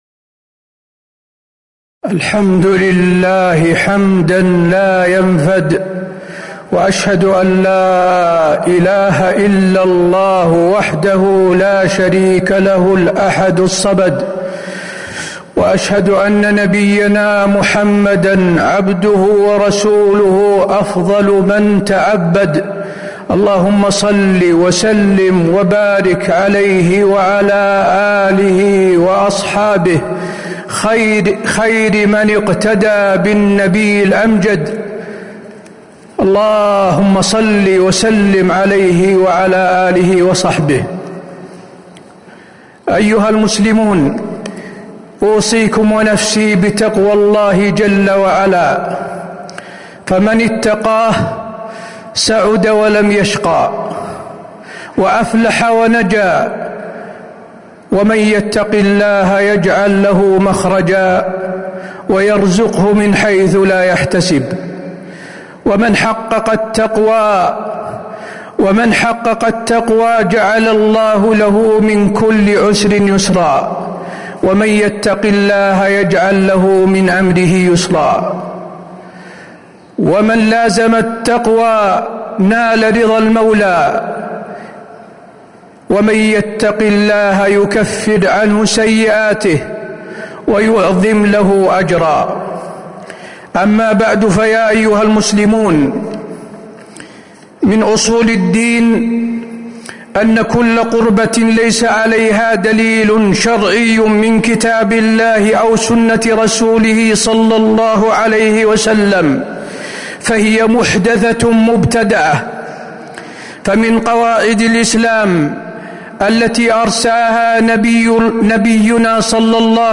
تاريخ النشر ١٠ رجب ١٤٤٦ هـ المكان: المسجد النبوي الشيخ: فضيلة الشيخ د. حسين بن عبدالعزيز آل الشيخ فضيلة الشيخ د. حسين بن عبدالعزيز آل الشيخ من أحكام شهر رجب